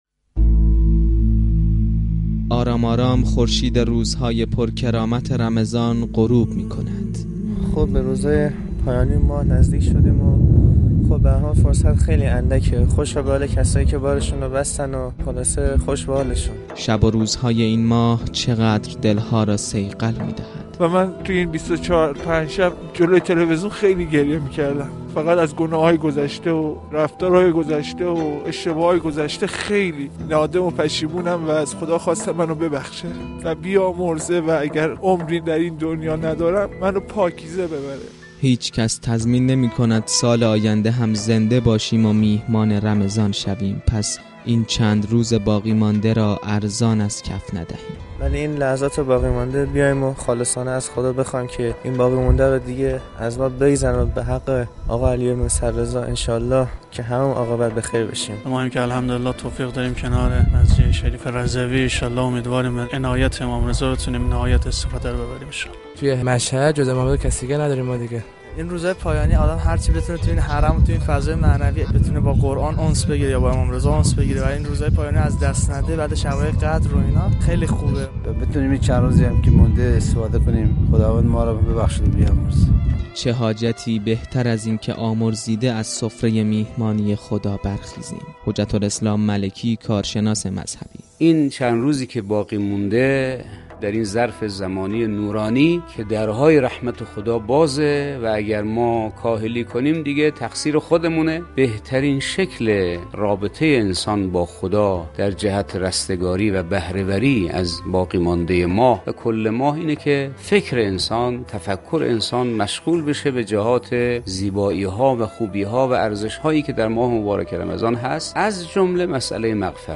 به گزارش خبر رادیو زیارت ، در حالیکه دو روز تا پایان ماه مبارک باقیست بسیاری از زائران و مجاوران حضرت رضا (ع) از برکات این ماه می گویند .